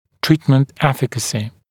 [‘triːtmənt ‘efɪkəsɪ][‘три:тмэнт ‘эфикэси]эффективность лечения